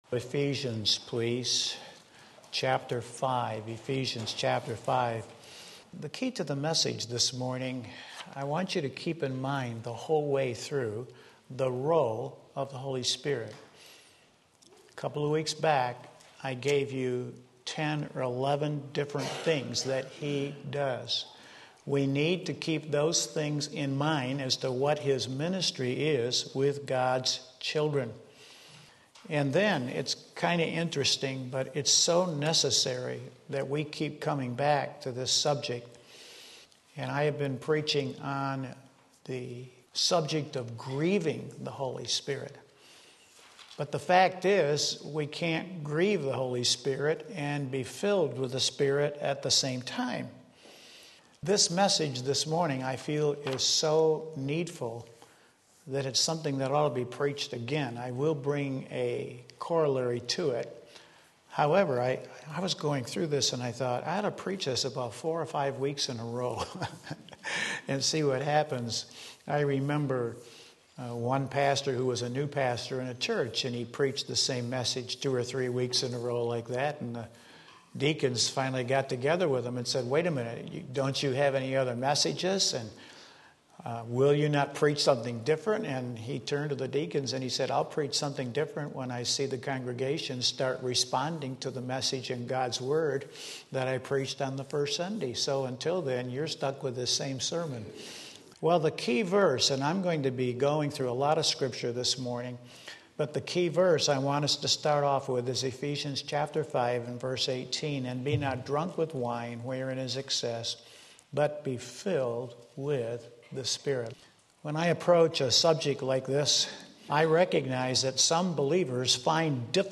Sermon Link
Ephesians 5:18 Sunday Morning Service